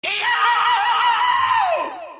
Peppino Pizza Tower Scream Sound Effect Free Download
Peppino Pizza Tower Scream